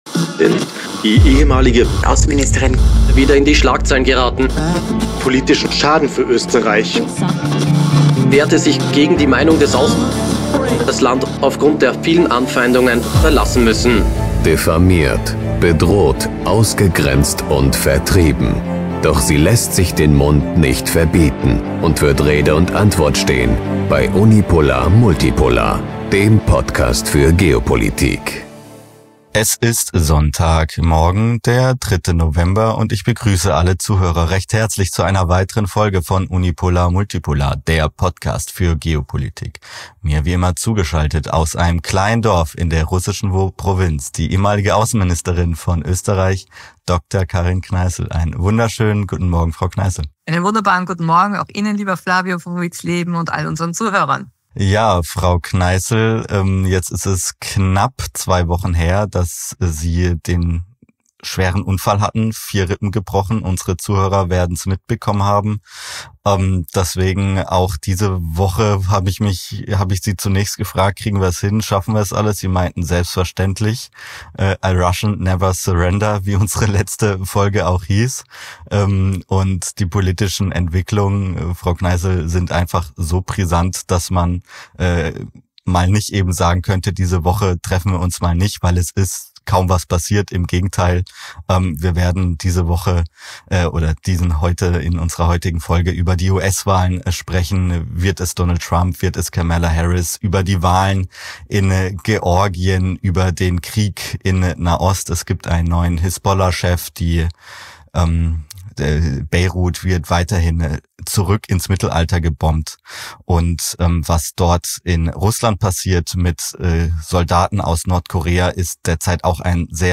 Außerdem diskutieren die beiden die Wahlen in Georgien und den Vorwurf, dass diese von Russland beeinflusst wurden.